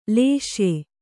♪ lēsye